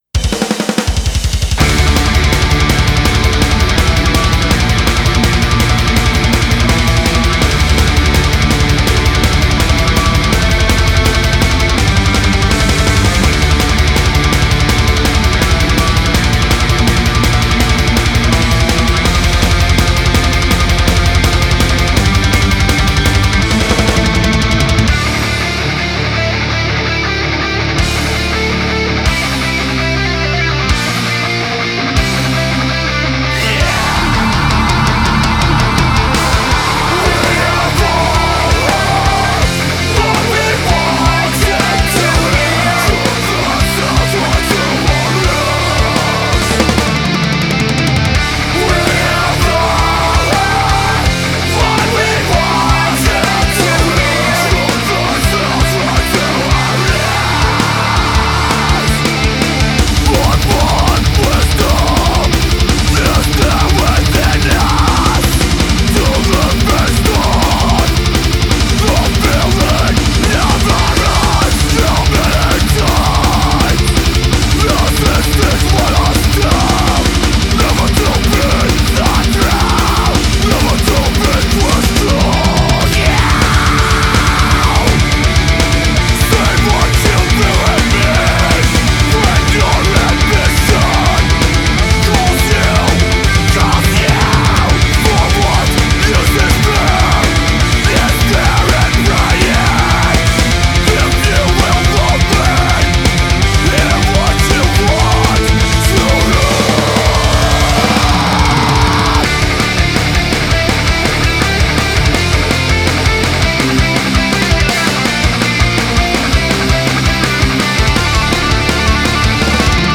Melodic Metalcore